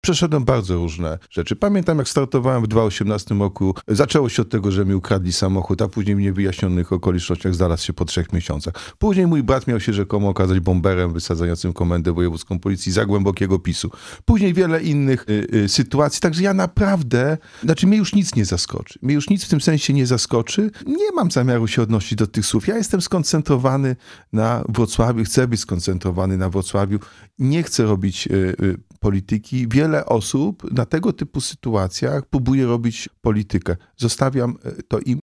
Projekt budżetu na 2025 r., audyt w piłkarskim Śląsku Wrocław, sprawa zarzutów ws. Collegium Humanum – to główne tematy w naszej rozmowie z prezydentem Wrocławia.